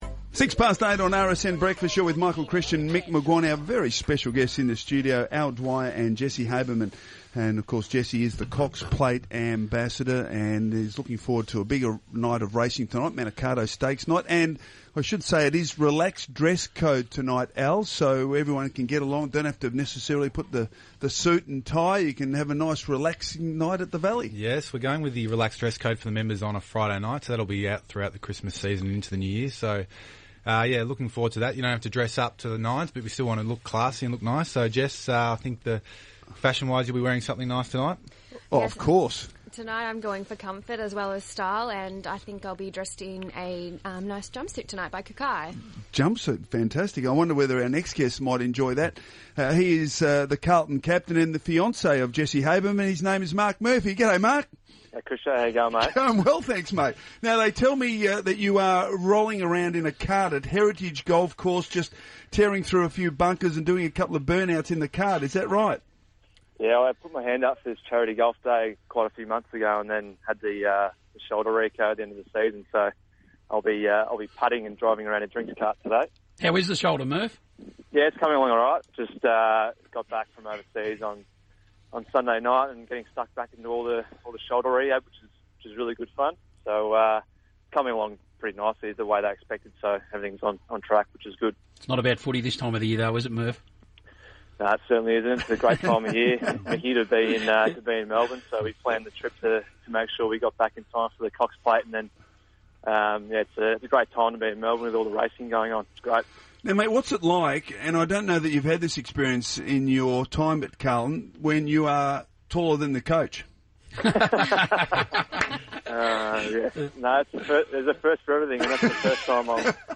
speaks with Radio Sport National during his off-season break.